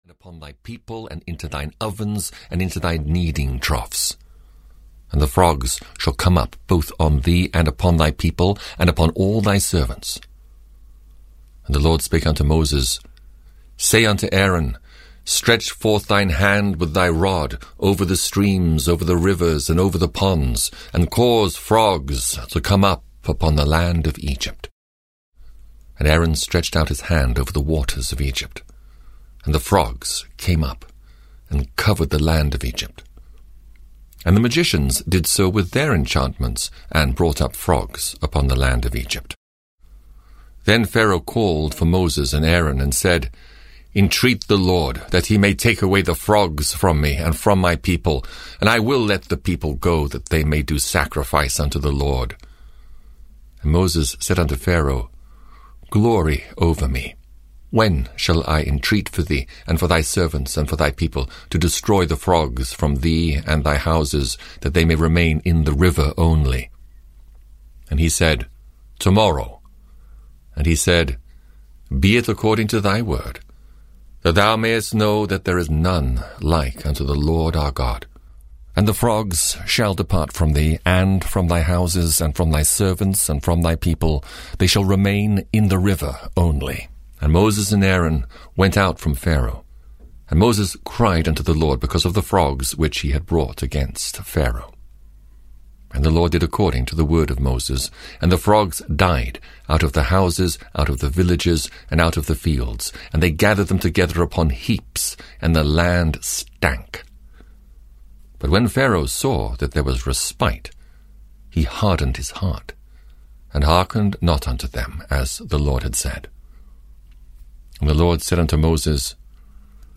The Old Testament 2 - Exodus (EN) audiokniha
Ukázka z knihy